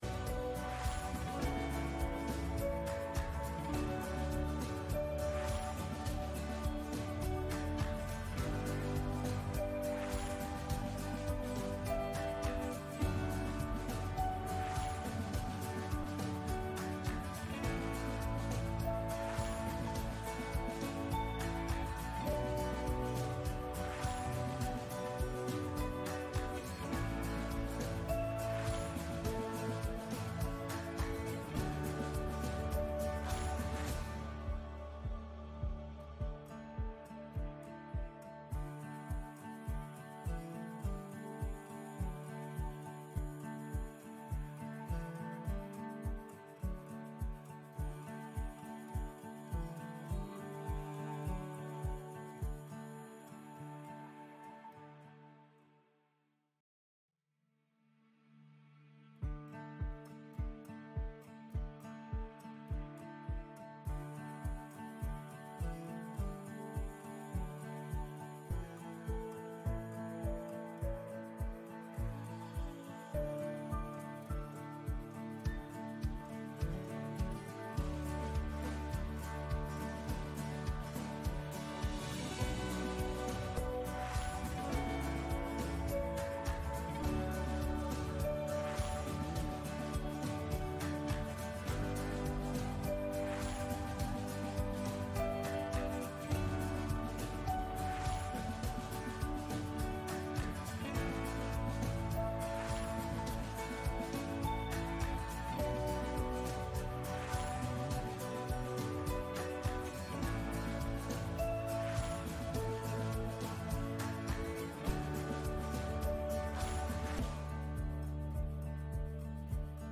Gottesdienst mit Abendmahl am 07. September aus der Christuskirche Altona